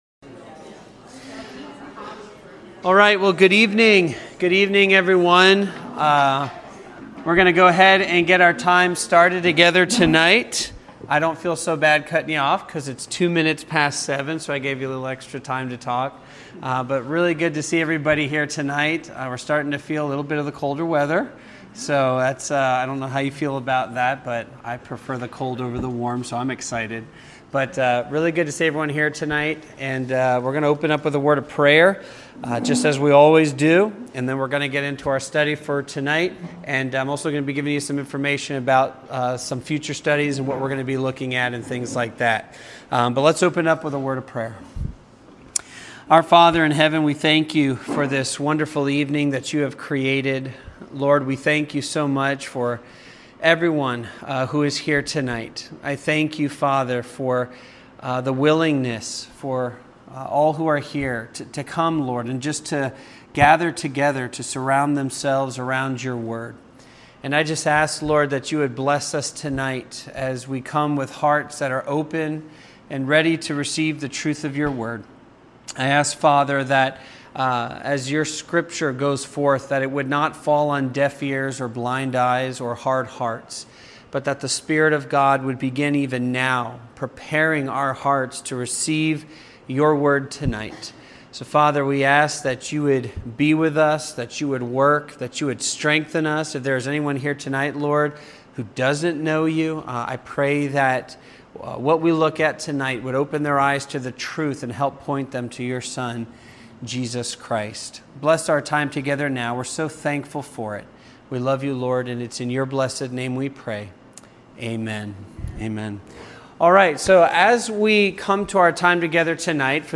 The Hive Bible Study